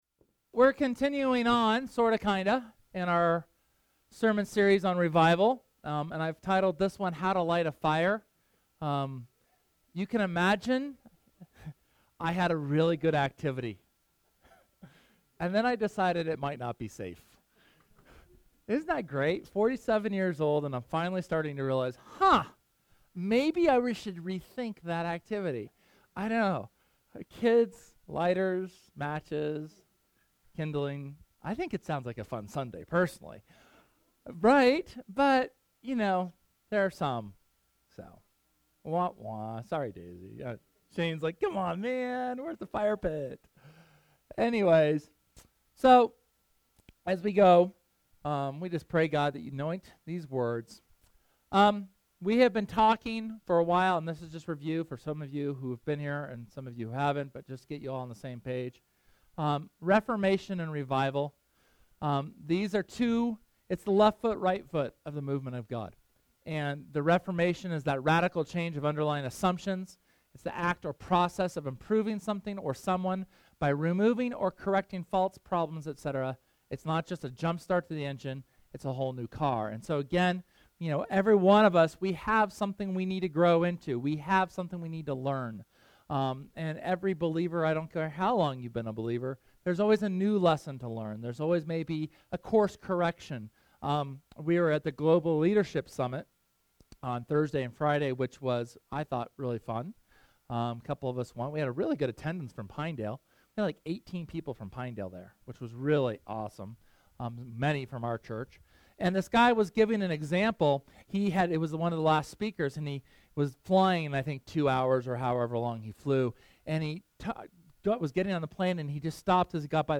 SERMON: Revival (4)